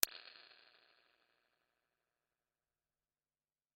吉他踏板的脉冲响应 " 圣杯弹簧3长
描述：这些声音是通过录制一个或多个吉他效果踏板的输出并输入10ms白噪声脉冲而产生的。如果踏板是单声道，则样本也是如此，反之亦然。
标签： 重20 对此 老板 混响 踏板 冲动 罗兰 延迟 生活 吉他 大厅 回响 口音 最大 ABLETON 圣洁 卷积 莫代尔 大盘
声道立体声